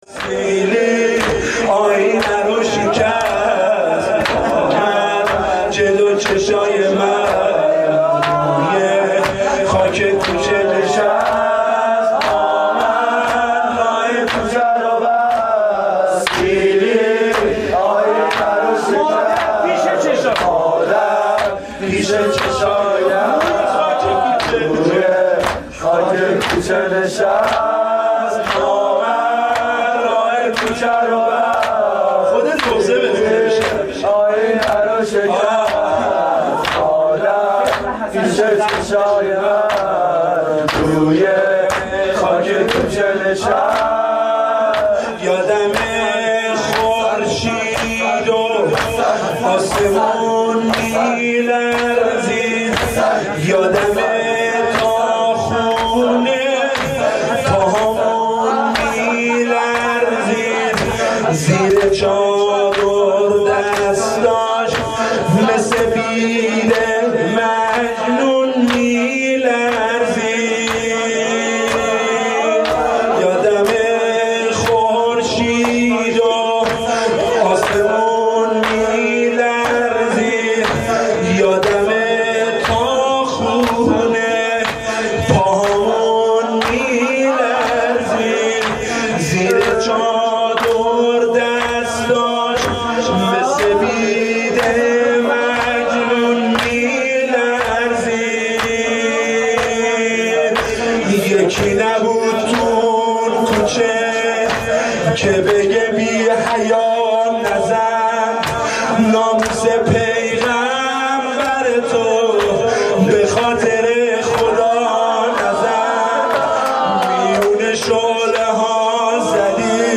هیئت رایت الهدی کمالشهر
نوای فاطمیه, مداحی فاطمیه